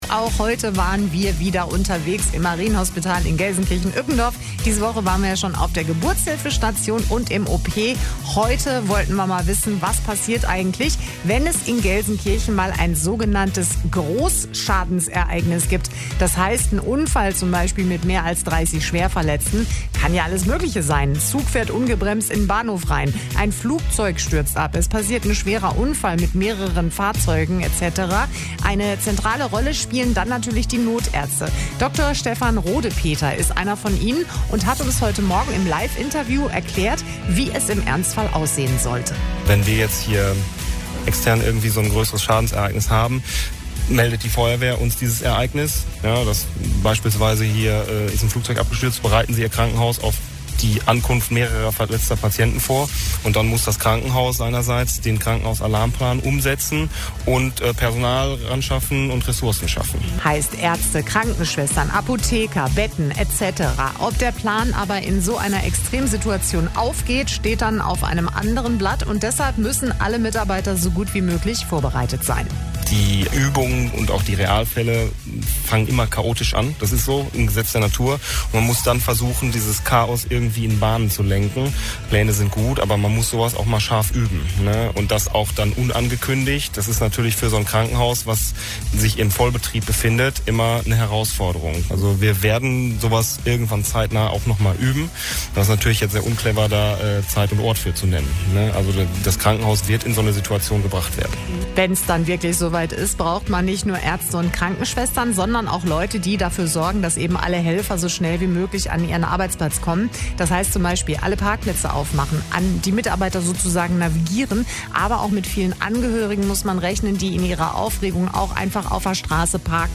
Auch heute waren wir wieder im Marienhospital in Gelsenkirchen-Ückendorf. Diese Woche waren wir schon auf der Geburtshilfe-Station und im OP. Heute wollten wir mal wissen, was passiert eigentlich, wenn es in Gelsenkirchen mal ein sogenanntes Großschadensereignis gibt, das heißt ein Unfall zum Beispiel mit mehr als 30 Schwerverletzten.